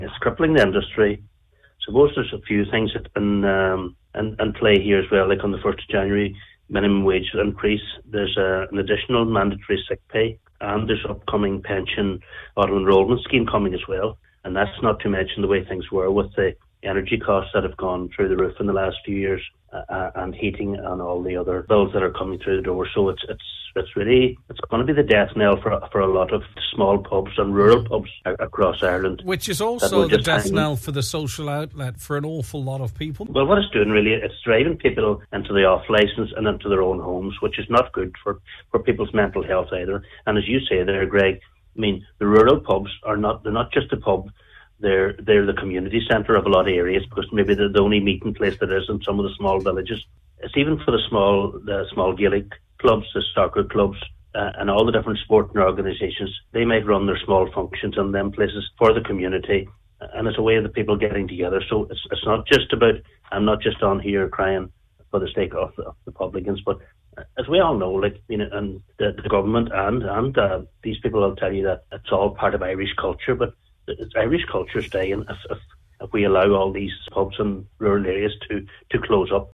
VFI Treasurer and Owner of Cheers Bar in Ballybofey, Cllr Martin Harley, says more people will end up drinking at home as a result: